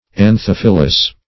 Anthophilous \An*thoph"i*lous\, a. [Gr.